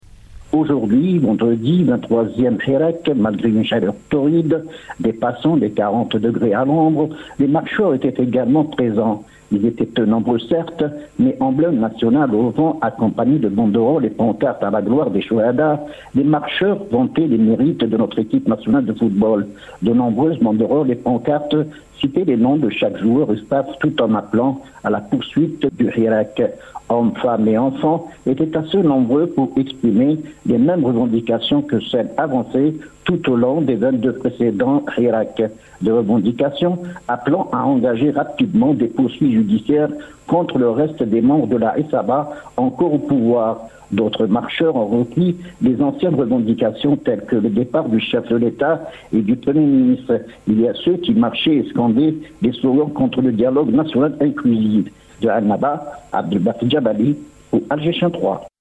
Compte rendu sur le hirak 23 à Annaba